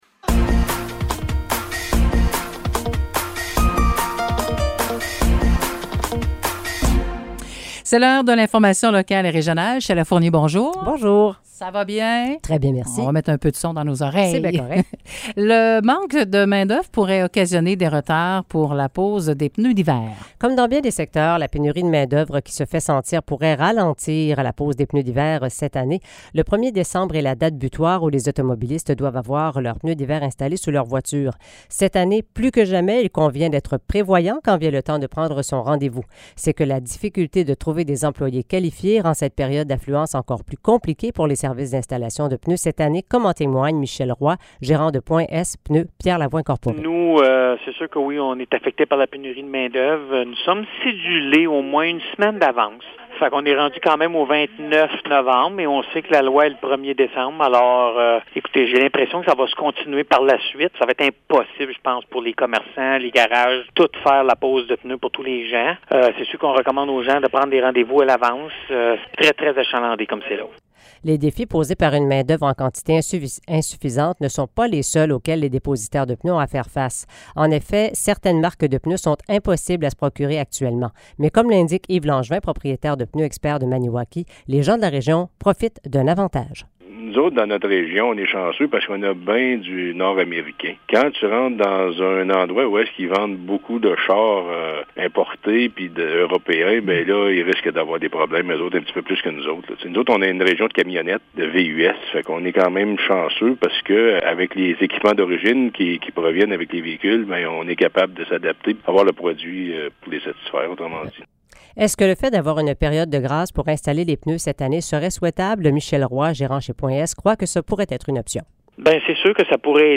Nouvelles locales - 23 novembre 2022 - 10 h